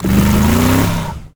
car-engine-load-2.ogg